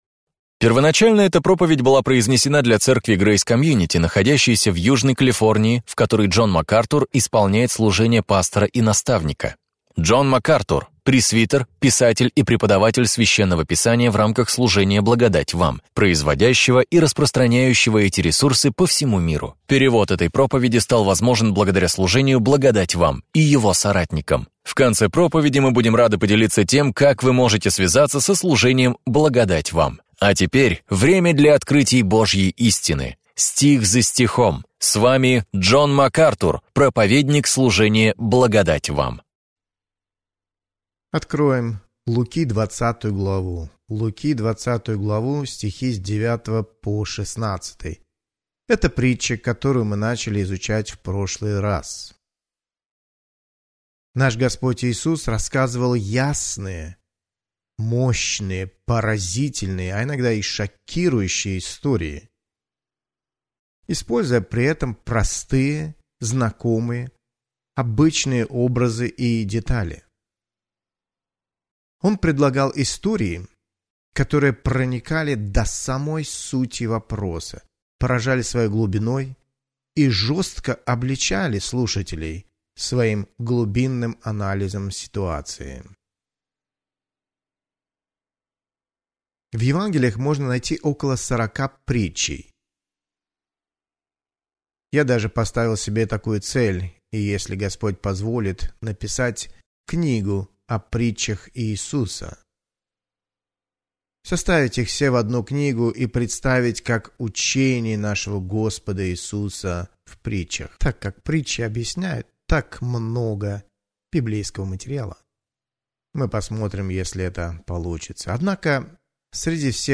Об этом и пойдет речь в проповеди Джона Макартура «Как общаться с еретиком».